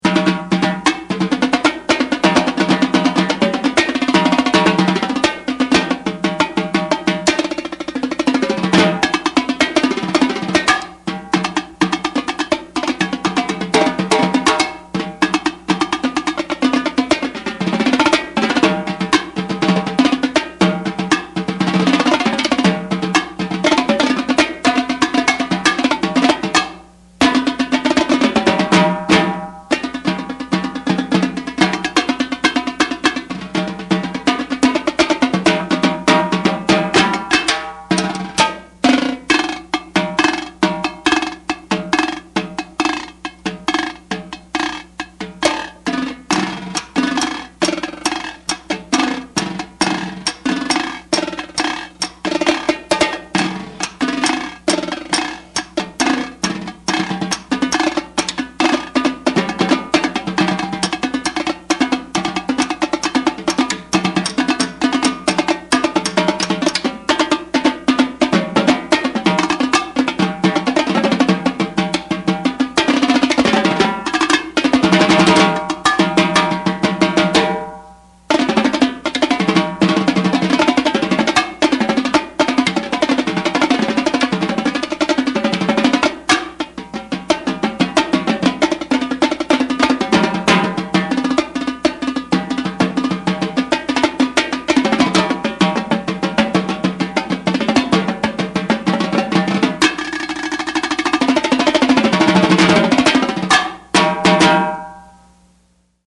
Voicing: Tenor Drum